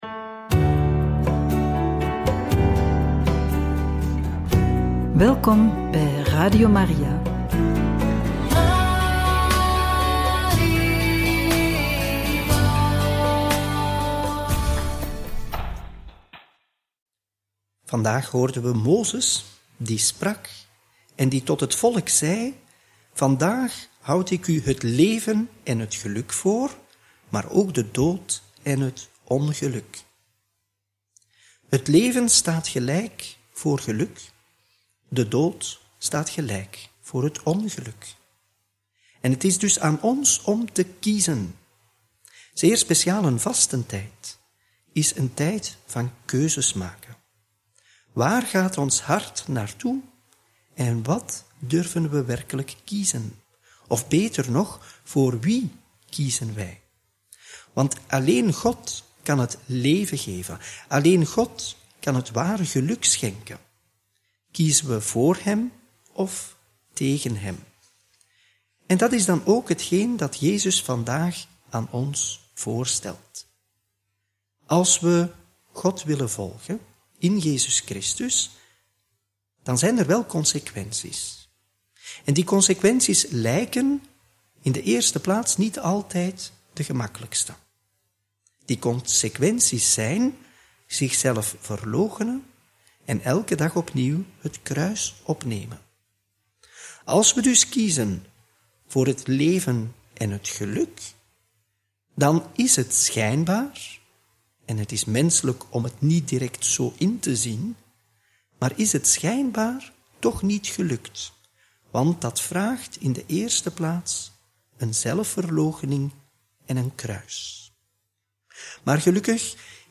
Homilie bij het Evangelie van donderdag 6 maart 2025 – Lc 9, 22-25